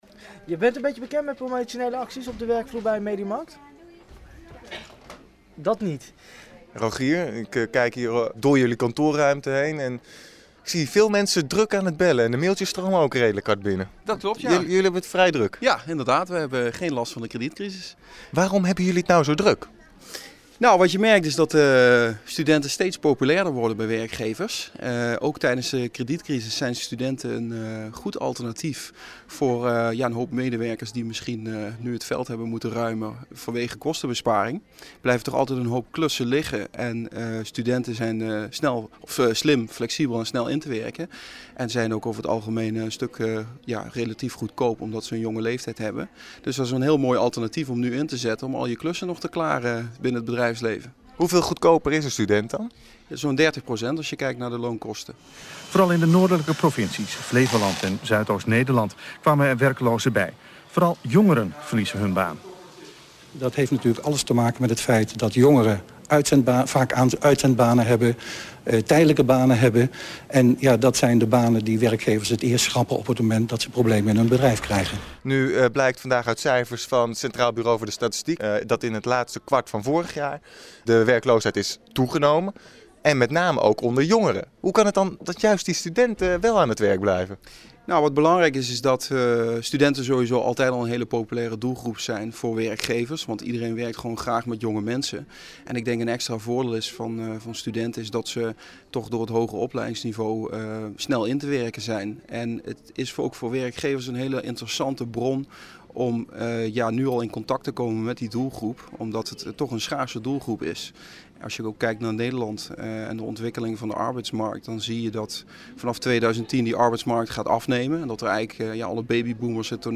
Afspelen radiocommercial